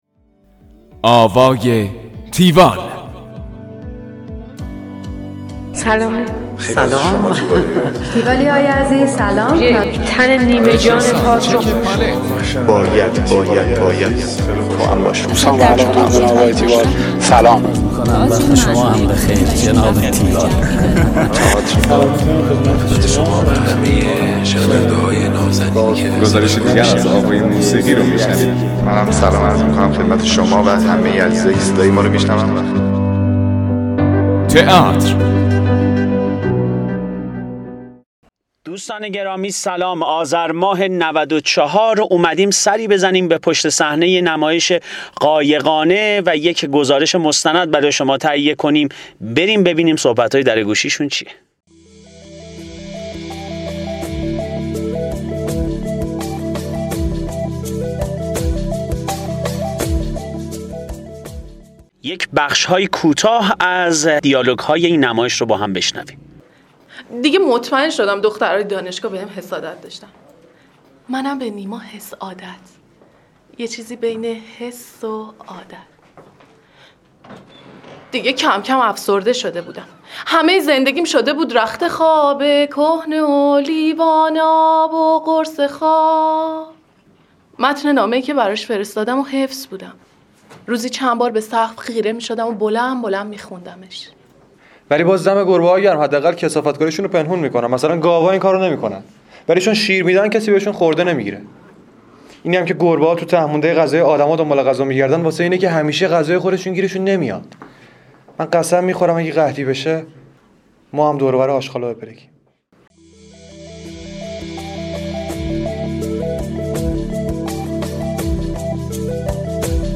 گزارش آوای تیوال از نمایش قایقانع
گفتگو با
همراه با بخش هایی از تمرین